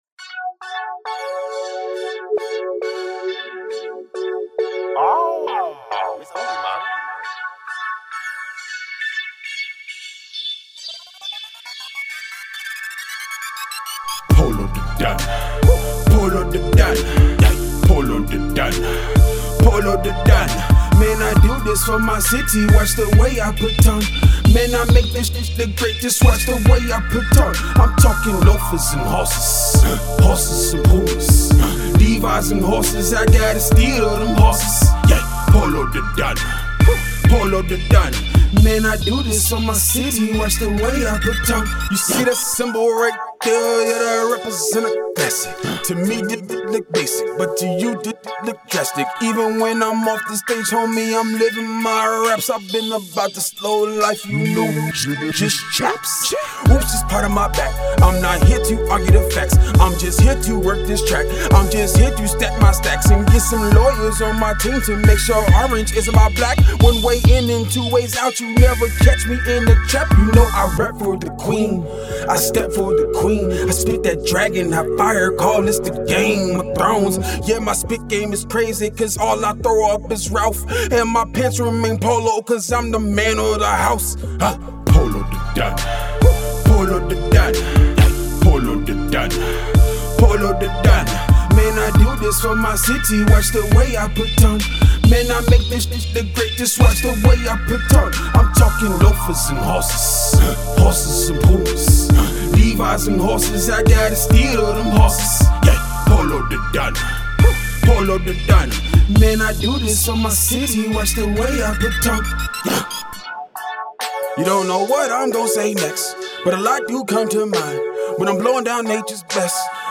Hiphop
A good track for the trap with clean lyrics!